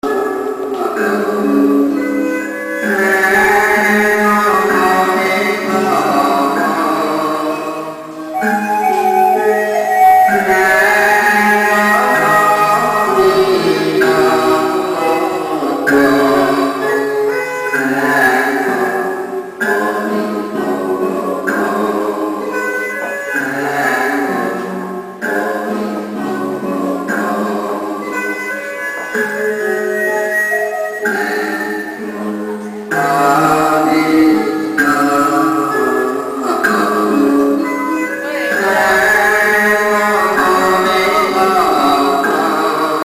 求佛教歌曲一首～ 今天去小游了一番，偶然听到一首 佛教歌曲 ，百思不得其解，故想到激动的版主和朋友们，特来求助！